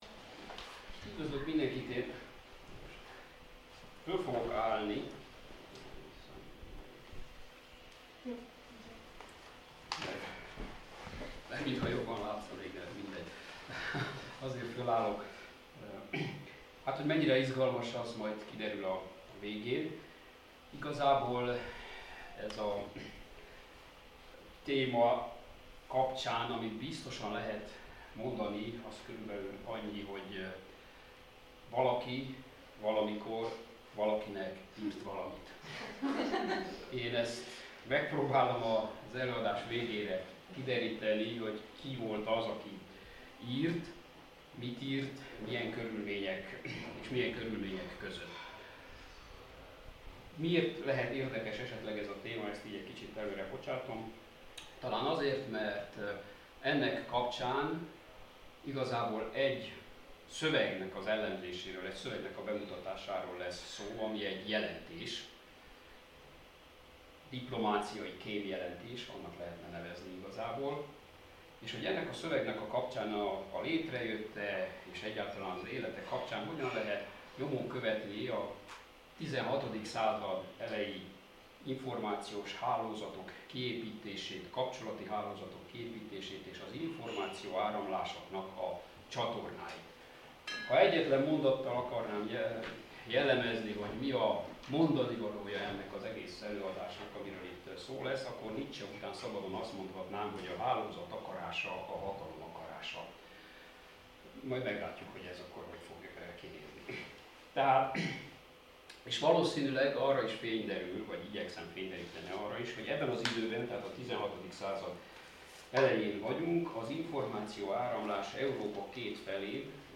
BTK ITI Reneszánsz Osztály felolvasóülései